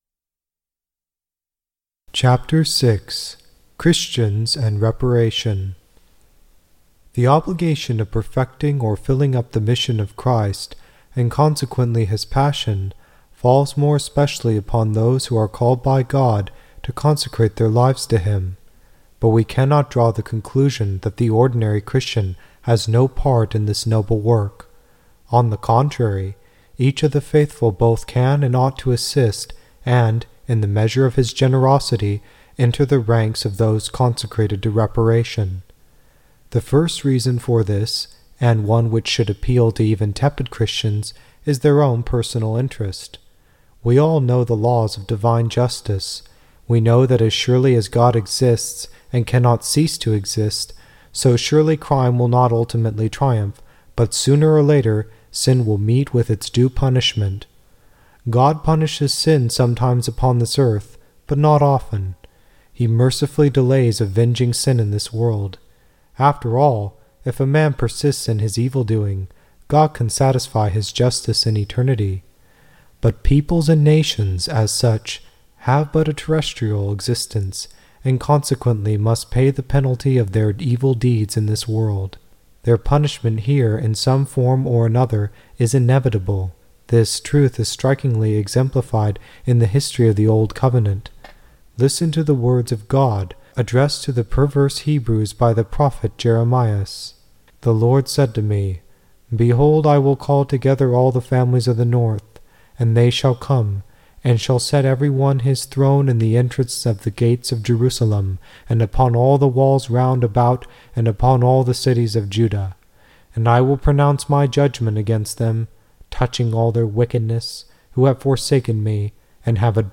Click Here to Download the Entire AudioBook on Reparation to the Sacred Heart of Jesus